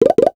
NOTIFICATION_Pop_11_mono.wav